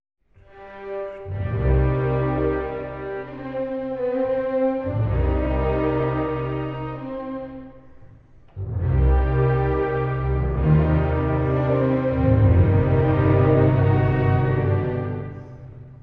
↑古い録音のため聴きづらいかもしれません！（以下同様）
Marcia funebre: Adagio assai
～葬送行進曲：十分に遅く～
何かを憂い、引きずるように、とても暗く進んでいきます。
最後は、こと切れるかのようにぷつりぷつりと途絶えます。
この楽章で興味深いのは、裏で常に叩かれる「タタタタン」というリズムです。